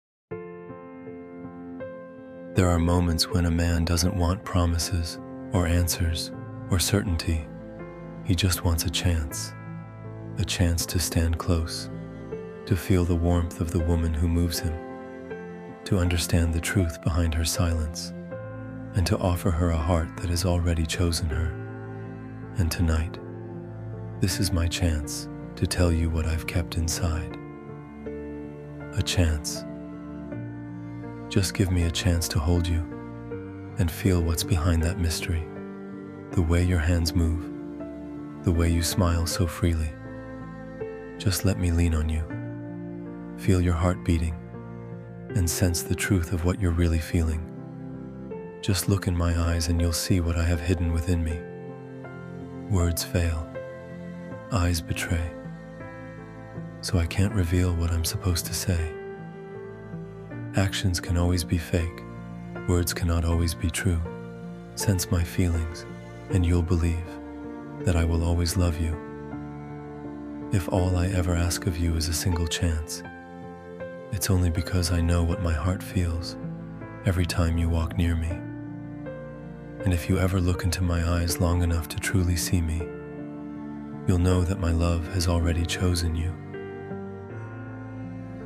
A Chance — Romantic Poem for Her (Male Spoken Word) We’ve all been there — caught between fear and longing, unsure whether to open our hearts again.
a-chance-romantic-poem-for-her-male-voice.mp3.mp3